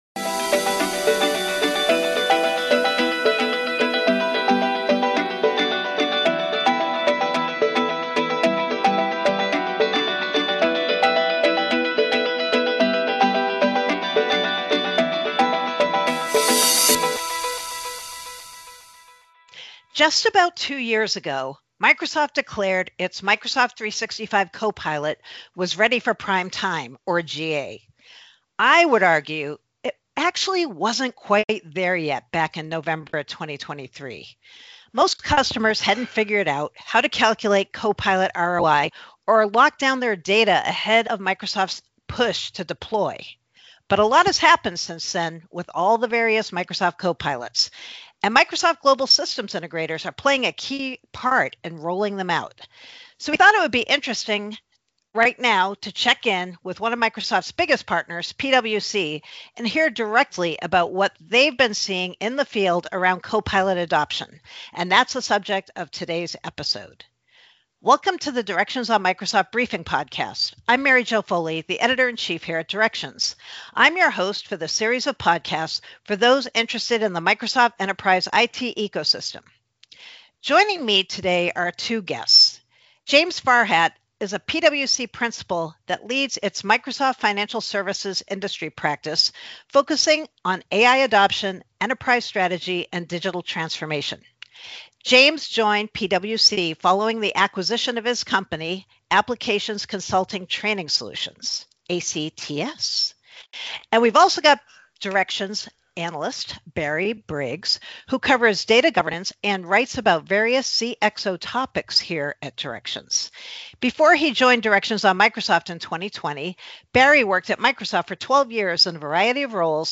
With more than 30 years of experience covering Microsoft, Directions on Microsoft provides unique perspectives about the shifts in Microsoft technologies, roadmaps and licensing policies that matter most to enterprise-size companies. Twice each month industry veteran Mary Jo Foley interviews a different Directions analyst for informed insights and advice to help you derive full value from your Microsoft investments.